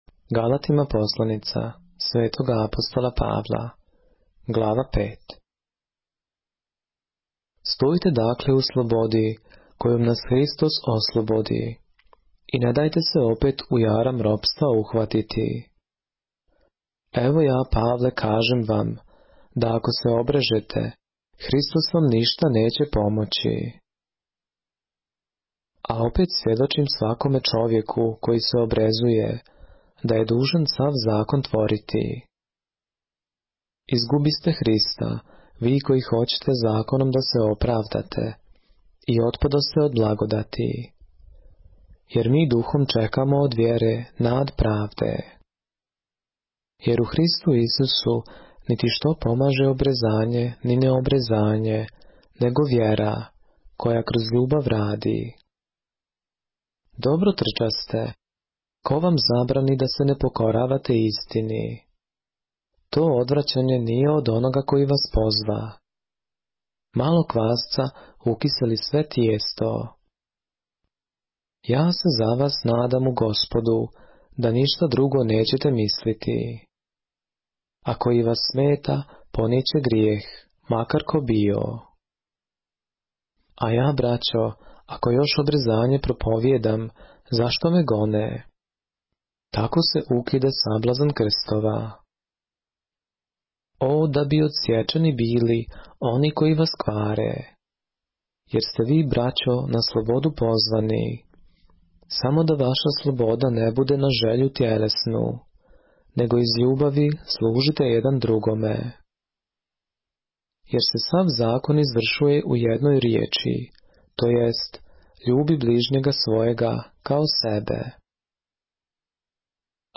поглавље српске Библије - са аудио нарације - Galatians, chapter 5 of the Holy Bible in the Serbian language